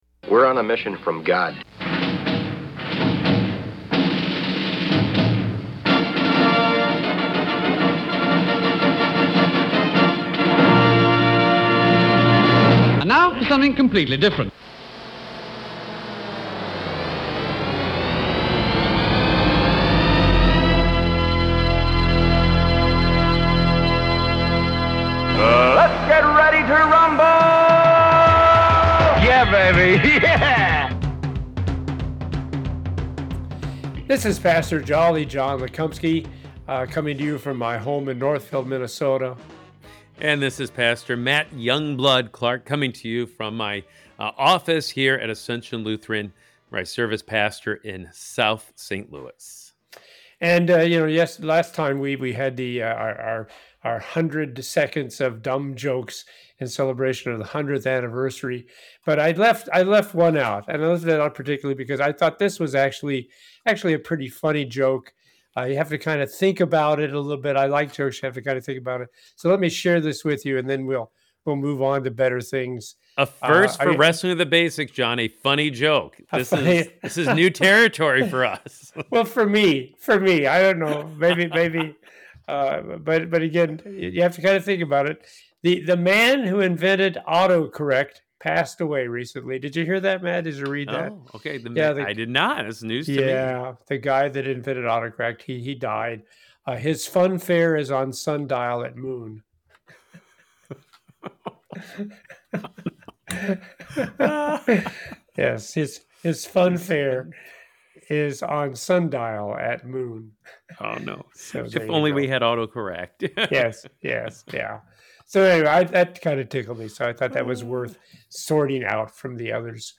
a humorous approach to Bible Study